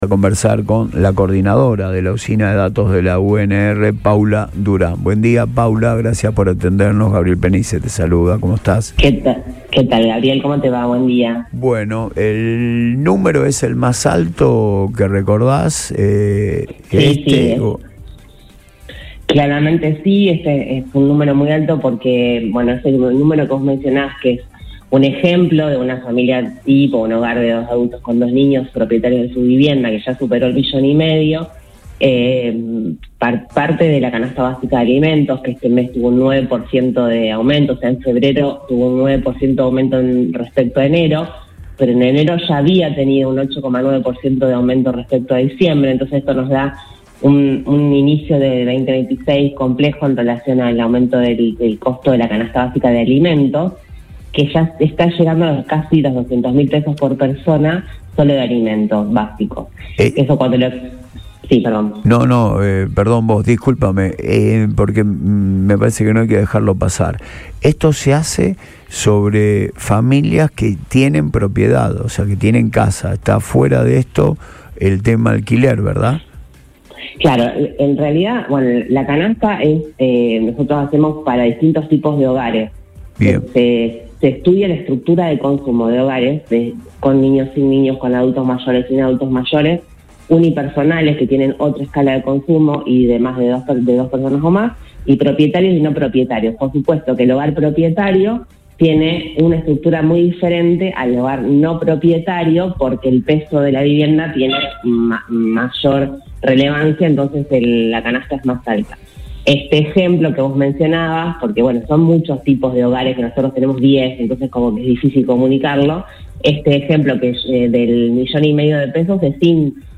advirtió en diálogo con el programa Antes de Todo de Radio Boing que la situación es preocupante